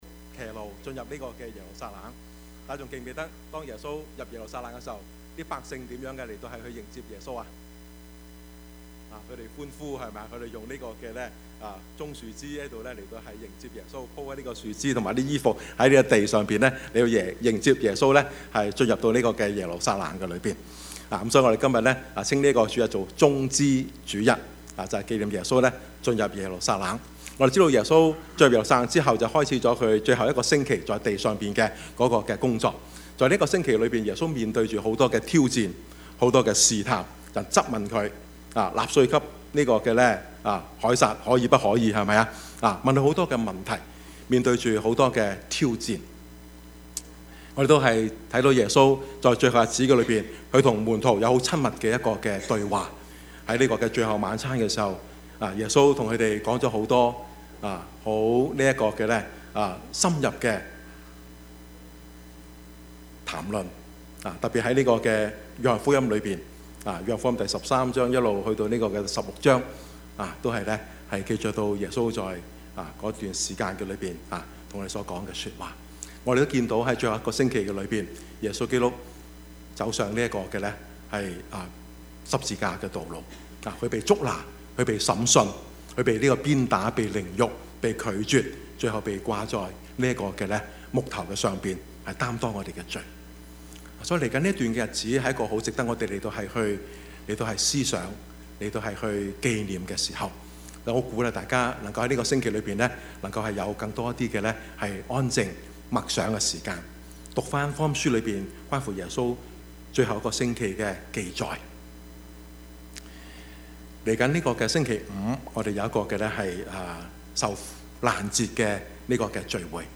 Service Type: 主日崇拜
Topics: 主日證道 « 反敗為勝 仍是有望 »